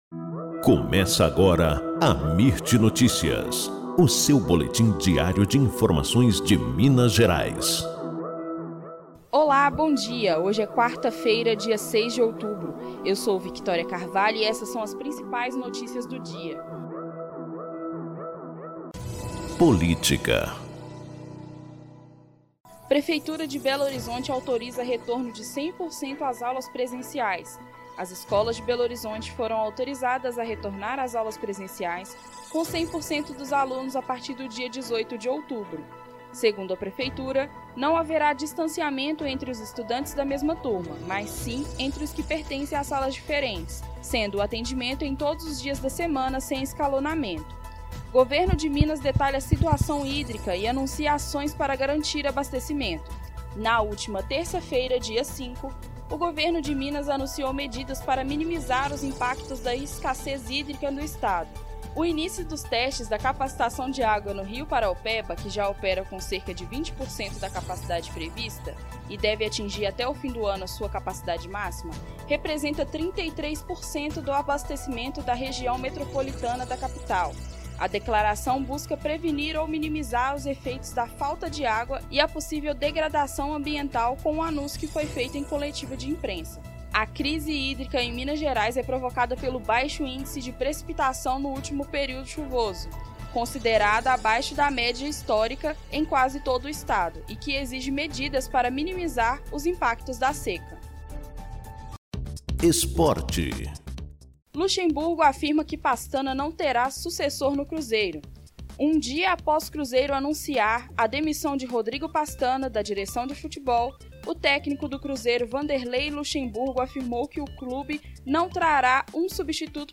Boletim Amirt Notícias – 06 de outubro